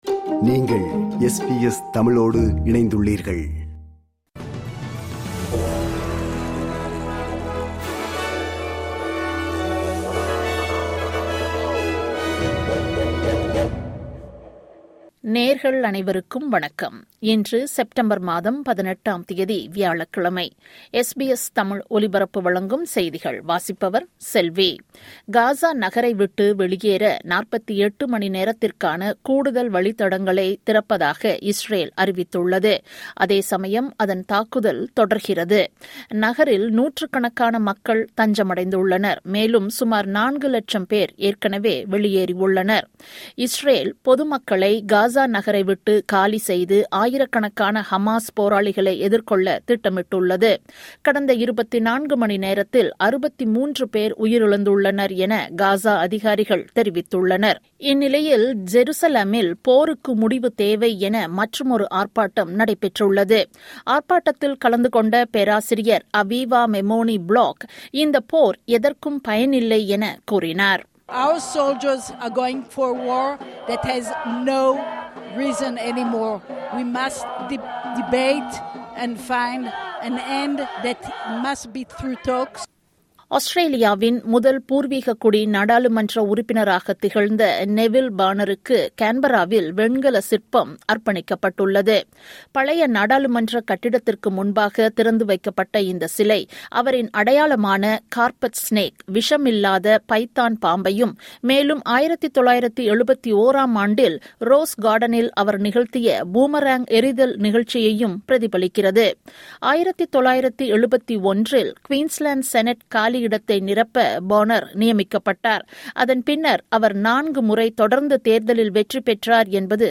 இன்றைய செய்திகள்: 18 செப்டம்பர் 2025 வியாழக்கிழமை
SBS தமிழ் ஒலிபரப்பின் இன்றைய (வியாழக்கிழமை 18/09/2025) செய்திகள்.